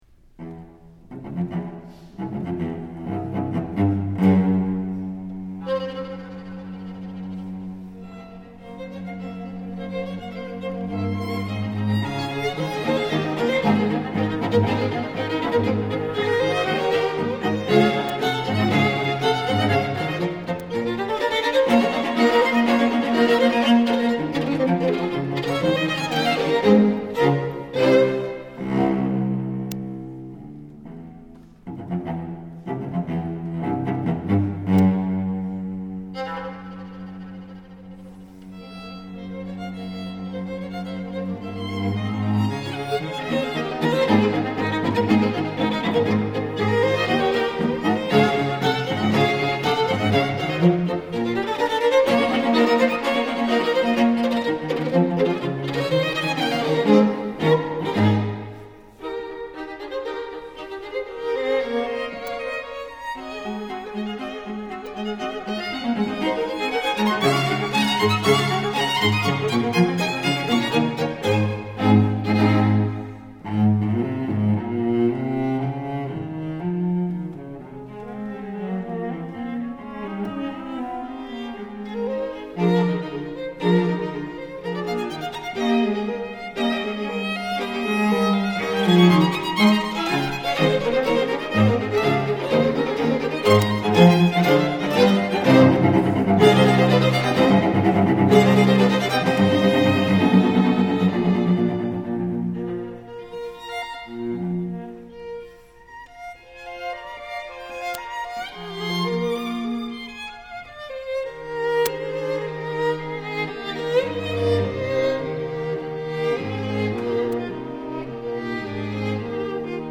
Dvorak, String Quartet in Ab Op.10